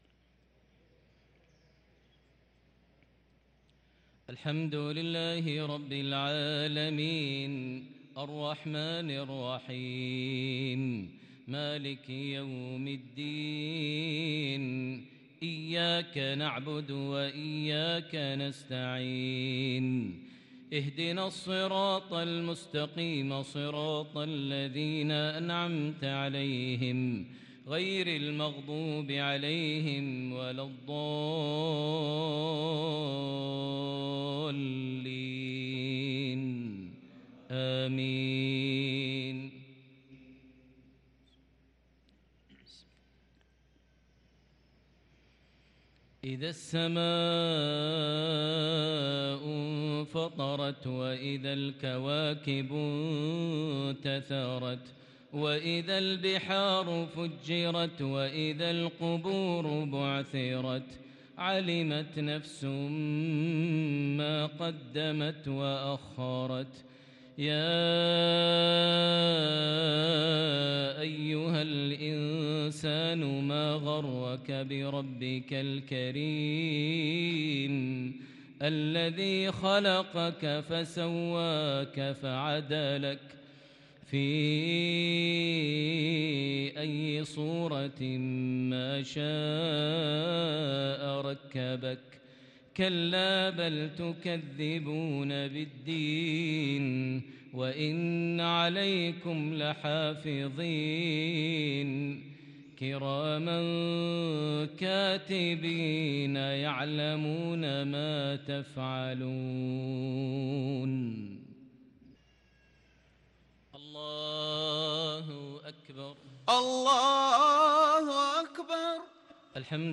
صلاة المغرب للقارئ ماهر المعيقلي 9 صفر 1444 هـ
تِلَاوَات الْحَرَمَيْن .